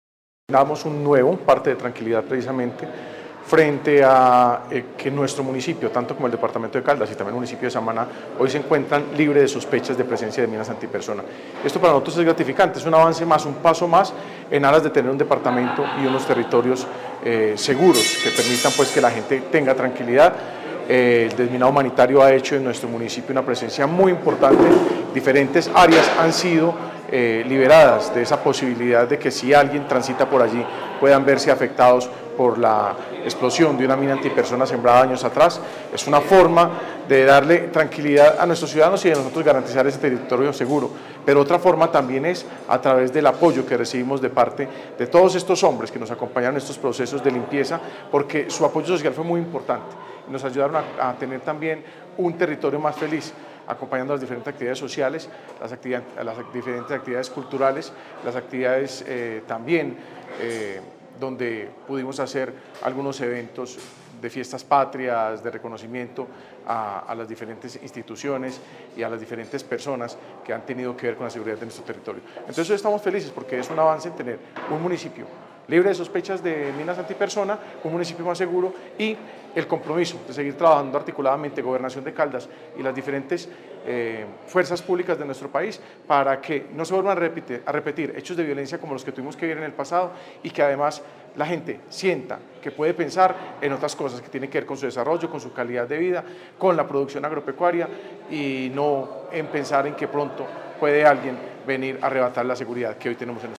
Jesús Iván Ospina, alcalde de Pensilvania